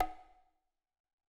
Sound / Effects / UI / African1.wav